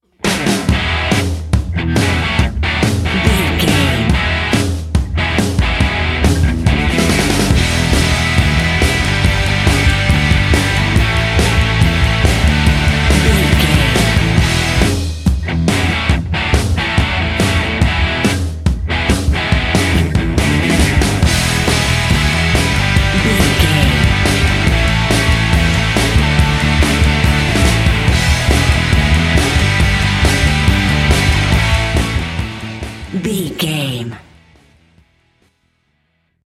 This rock track is ideal for action games and sport games
Ionian/Major
cool
powerful
energetic
heavy
electric guitar
bass guitar
drums
classic rock